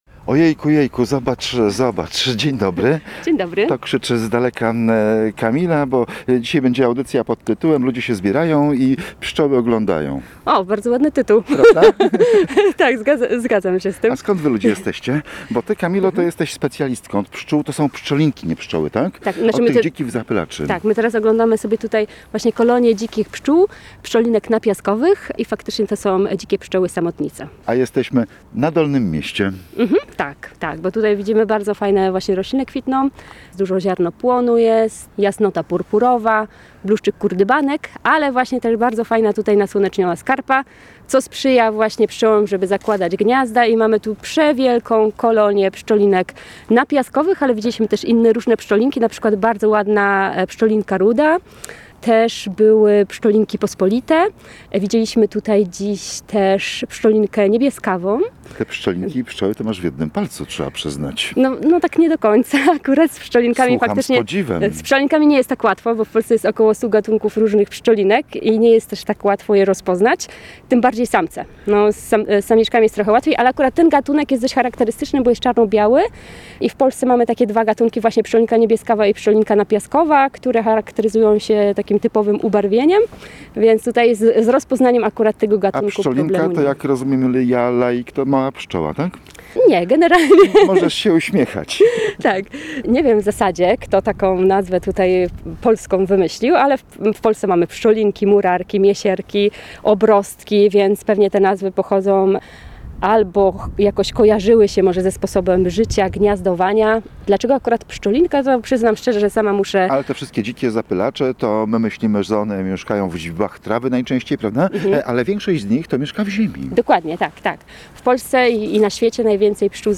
spotykaliśmy się na spacerze po Dolnym Mieście w Gdańsku.